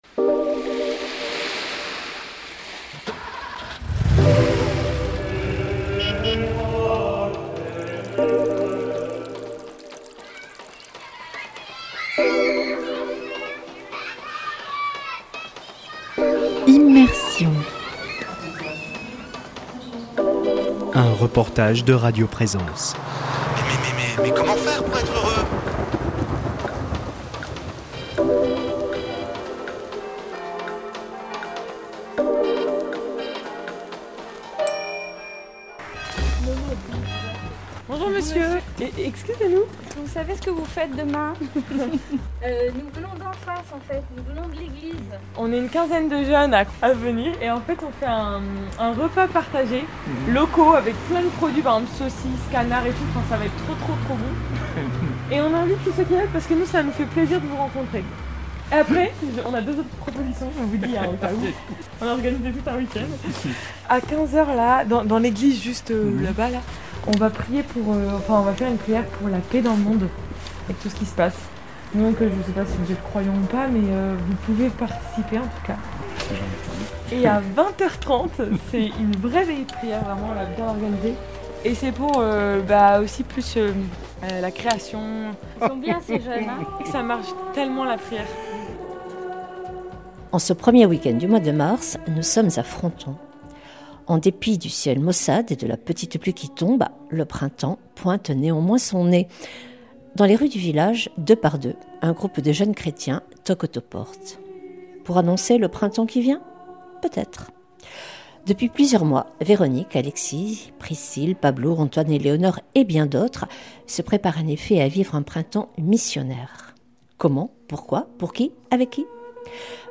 [ Rediffusion ] En ce premier we du mois de mars nous sommes à Fronton. En dépit du ciel maussade et de la petite pluie qui tombe, le printemps pointe néanmoins son nez. Dans les rues du village, deux par deux, un groupe de jeunes chrétiens toquent aux portes.